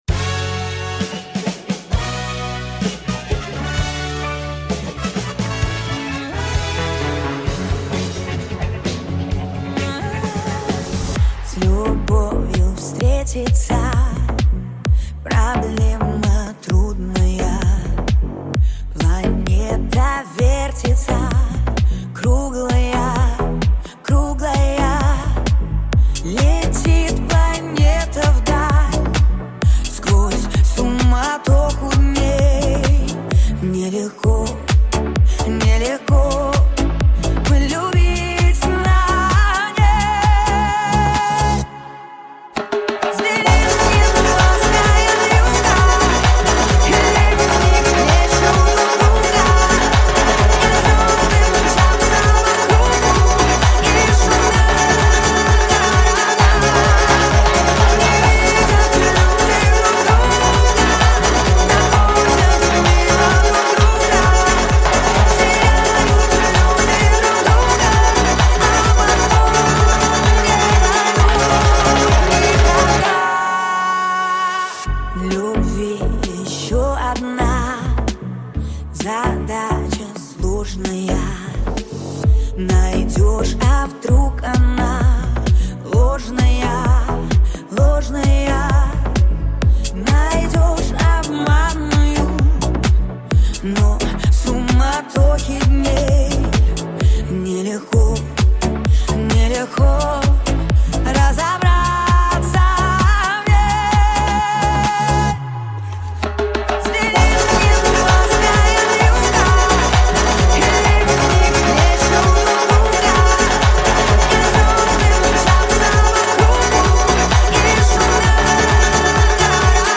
новая версия 2026 лезгинка ремикс